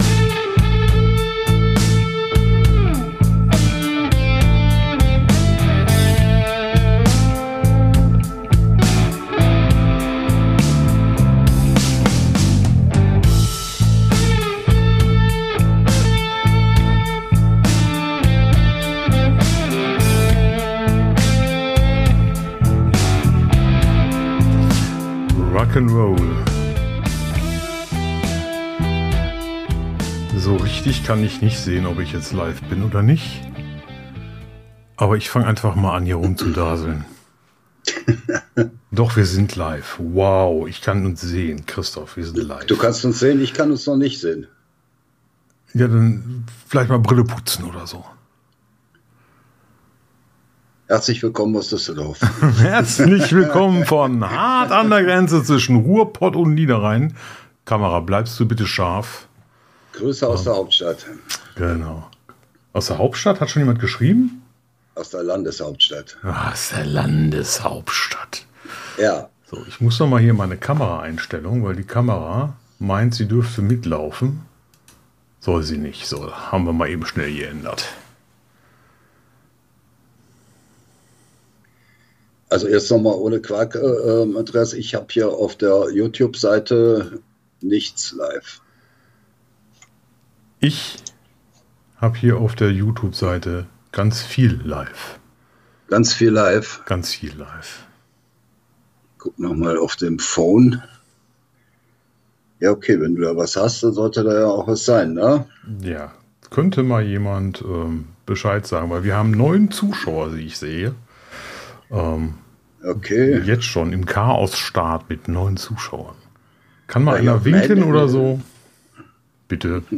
Aufzeichnung vom Live am Sonntag.